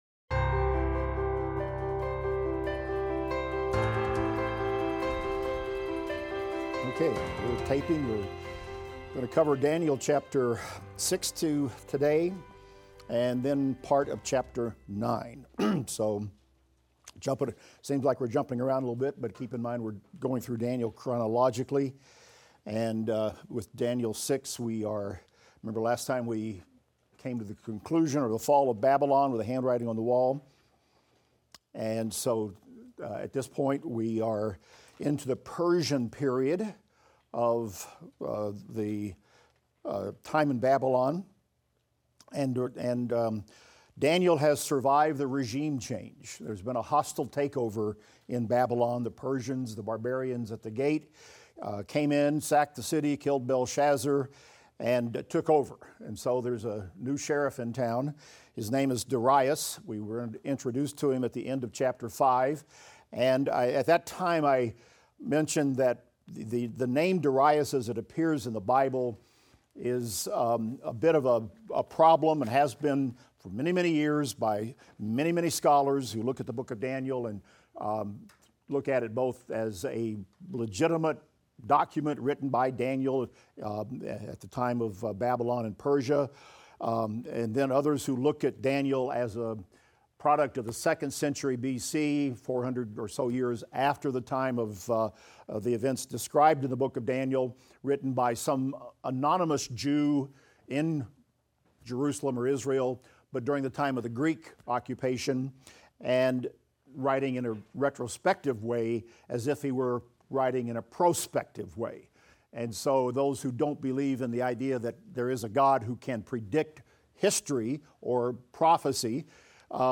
Daniel - Lecture 14 - audio.mp3